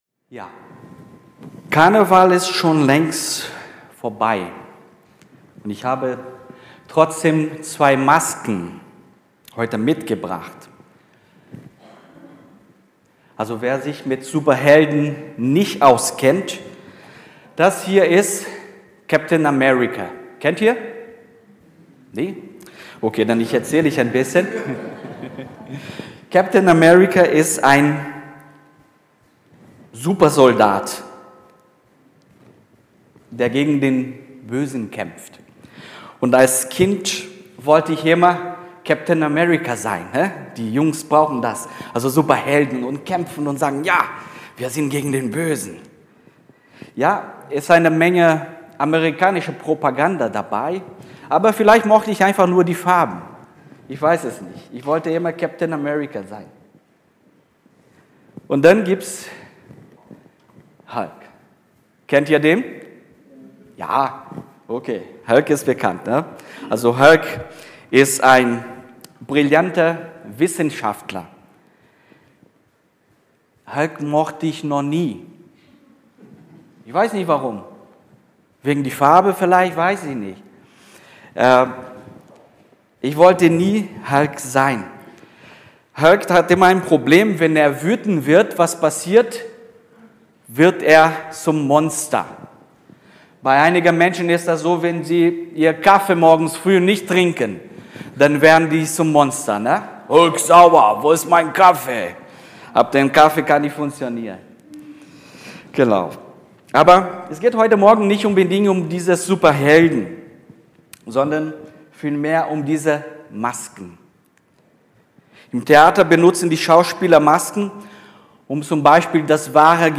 Predigten über den christlichen Glauben und Leben